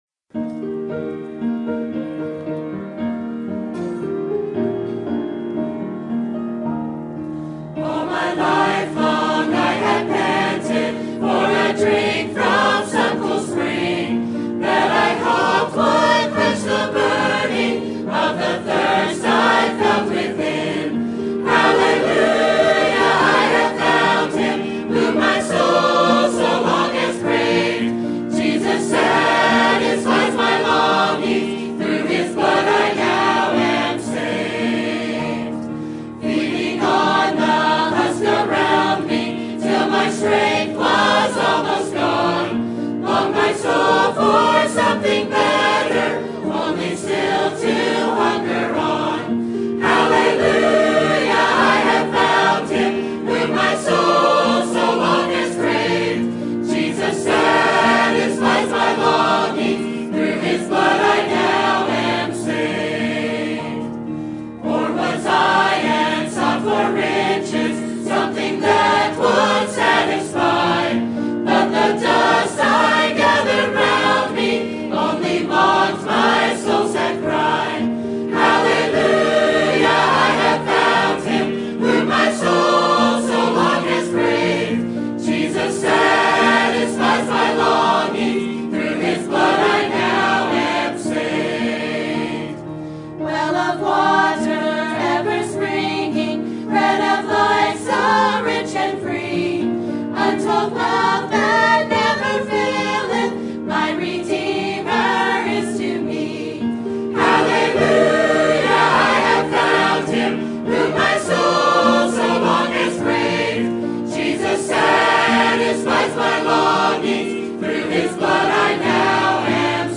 Sermon Topic: Life of Kings and Prophets Sermon Type: Series Sermon Audio: Sermon download: Download (26.7 MB) Sermon Tags: 1 Kings Kings Prophets Elisha